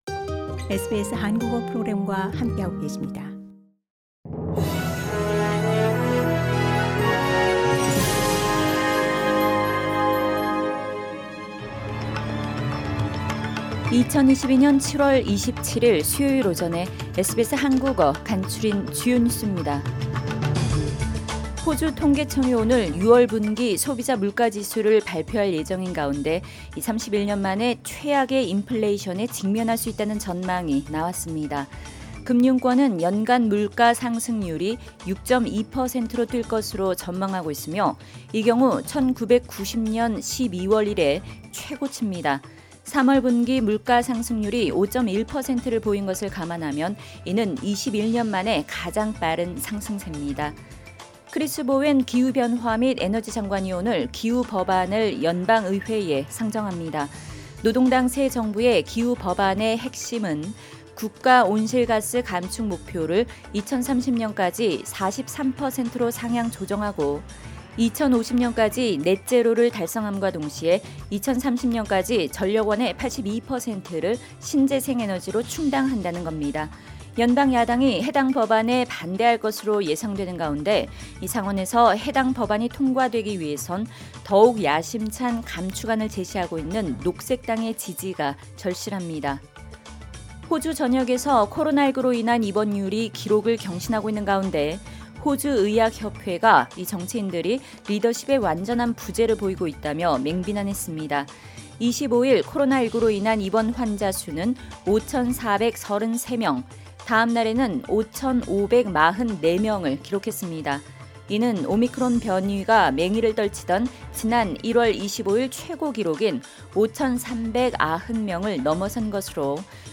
SBS 한국어 아침 뉴스: 2022년 7월 27일 수요일
2022년 7월 27일 수요일 아침 SBS 한국어 간추린 주요 뉴스입니다.